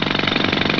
Drill.wav